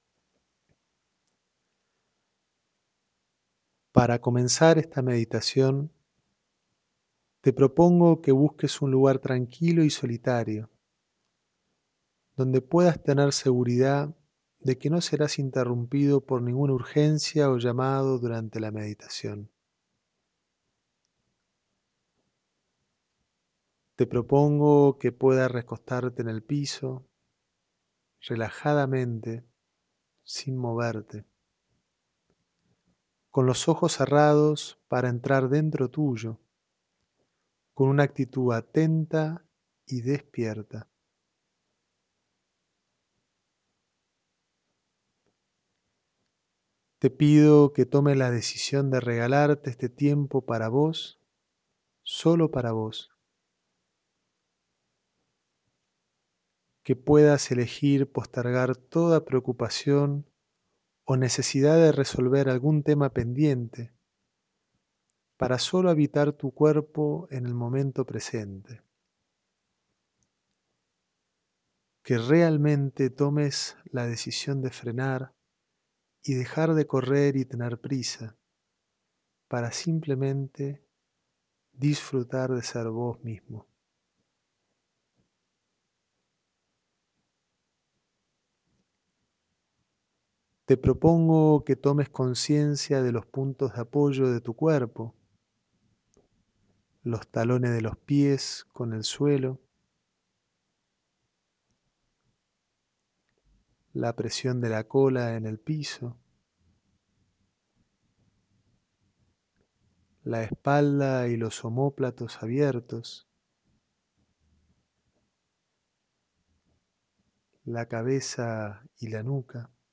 Mindfulness. Escaneo corporal 15 minutos.